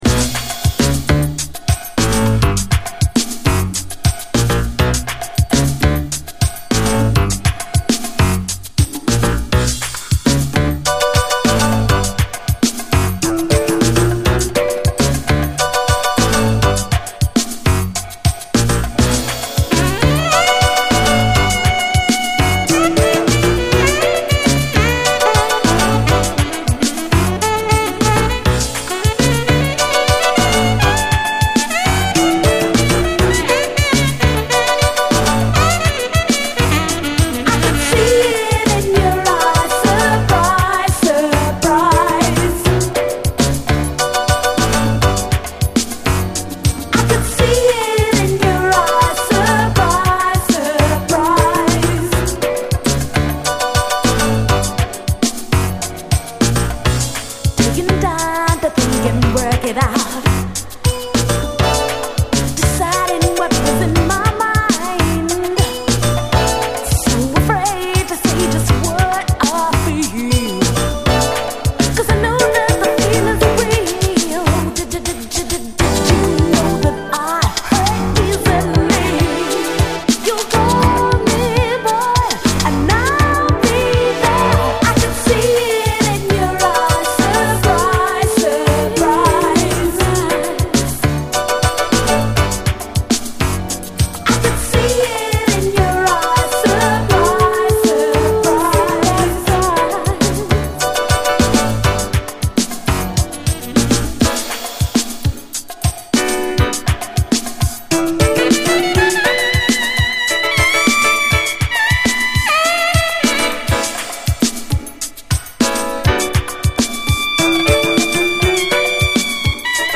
SOUL, 70's～ SOUL, DISCO
をダンサブルなビートのシンセ・ソウルに仕上げたリミックス！
UKの80’Sアーバン・ソウル・デュオ！
UKストリート・ソウル〜シンセ・ブギー・ファンにオススメのサウンドです！